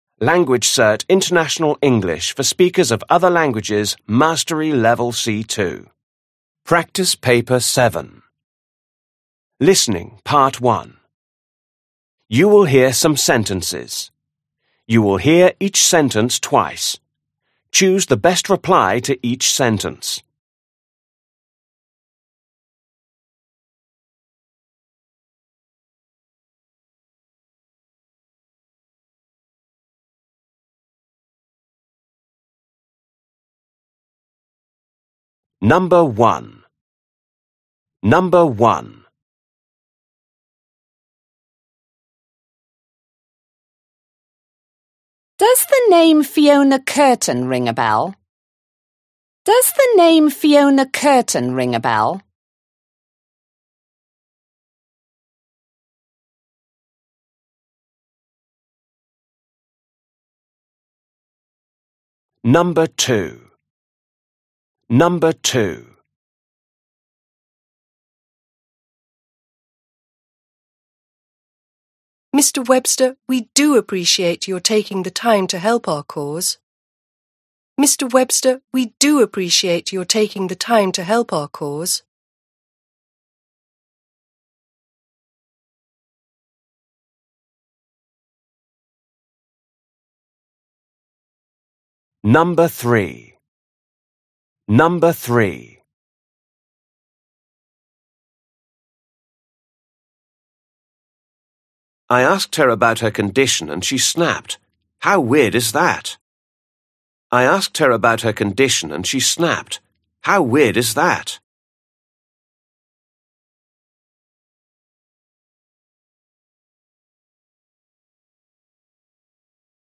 You will hear some sentences. You will hear each sentence twice.